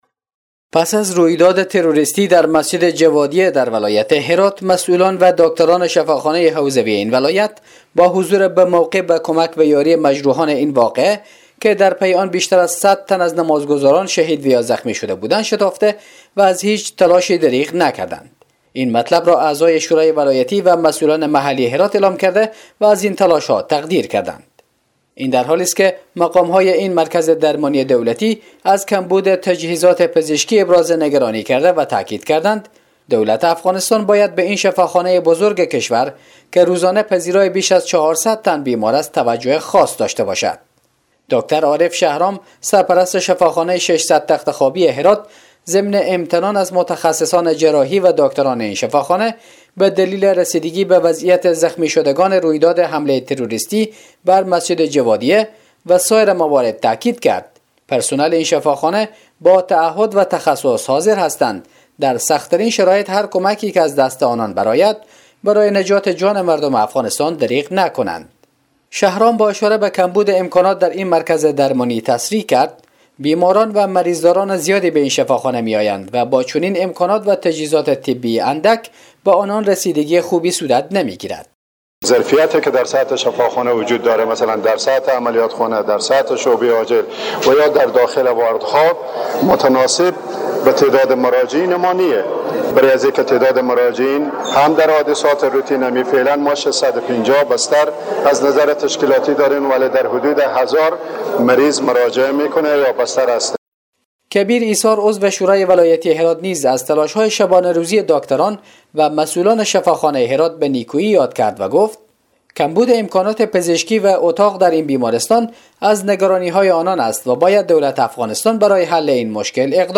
گزارش : مشکلات و کمبود امکانات پزشکی در شفاخانه هرات